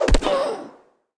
Player Fall Over Death Sound Effect
Download a high-quality player fall over death sound effect.
player-fall-over-death.mp3